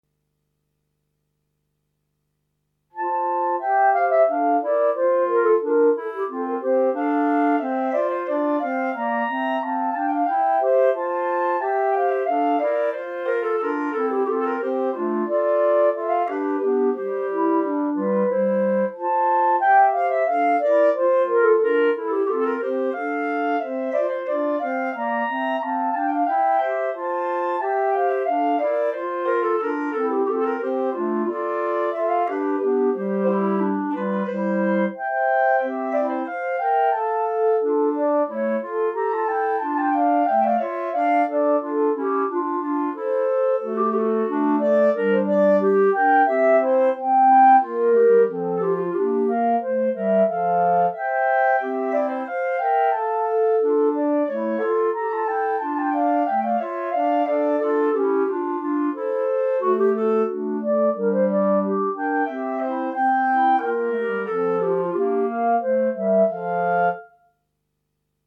minus Bass Clarinet